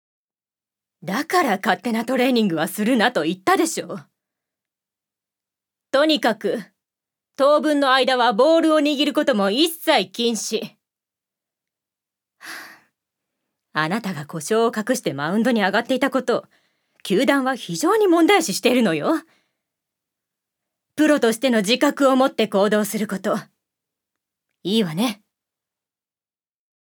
女性タレント
セリフ３